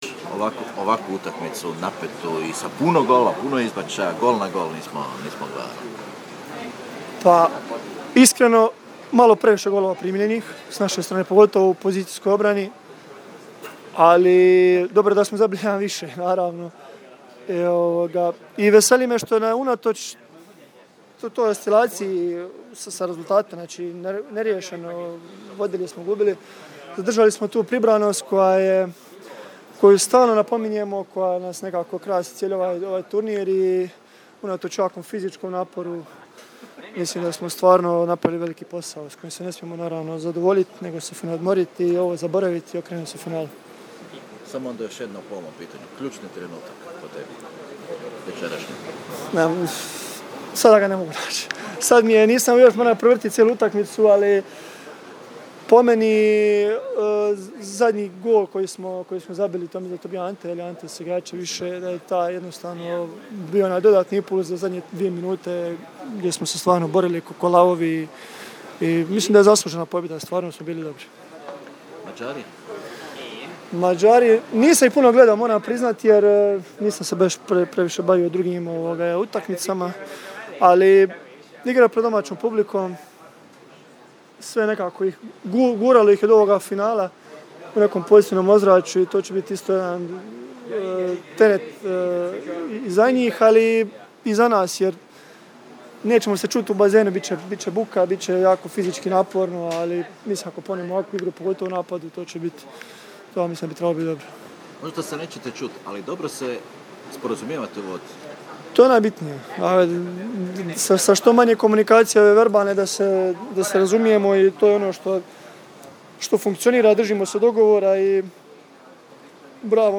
IZJAVE POBJEDNIKA: